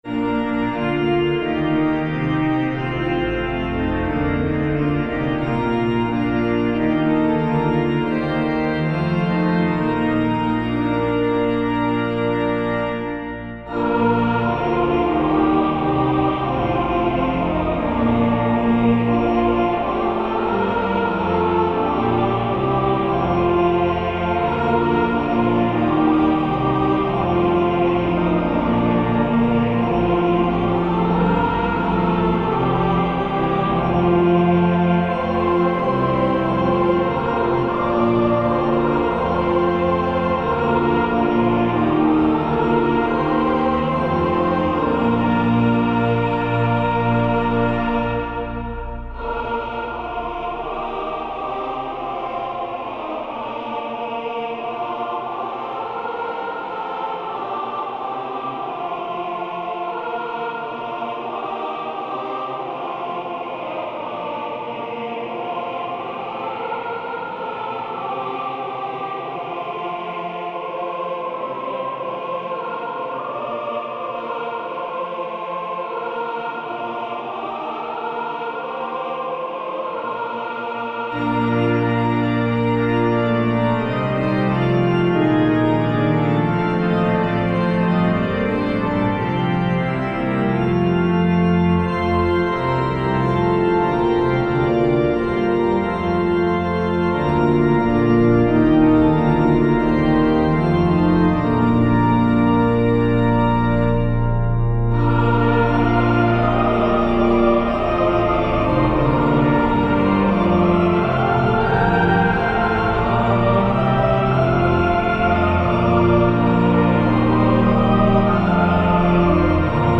Descant to the hymn 'Who are these like stars appearing' set to the tune ZEUCH MICH, also known as ALL SAINTS, ALL SAINTS OLD, and DARNSTADT.